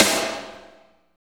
53.02 SNR.wav